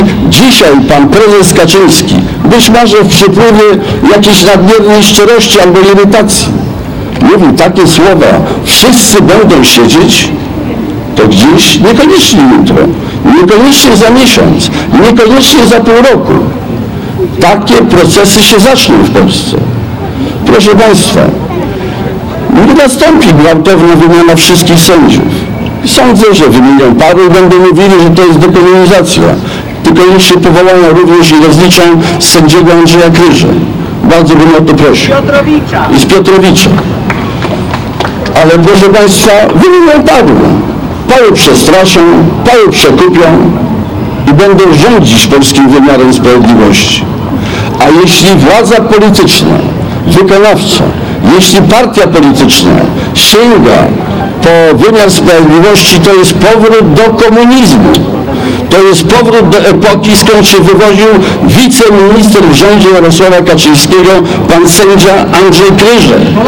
Ponad 200 osób na czele z Bronisławem Komorowskim, byłym prezydentem RP protestowało w czwartek (20.07)  w Augustowie w obronie demokracji.
Podczas swojego wystąpienia Bronisław Komorowski mówił, że sytuacja, w której władz sięga po sądy, oznacza powrót do komunizmu.
protest-w-obronie-demokracji-1.mp3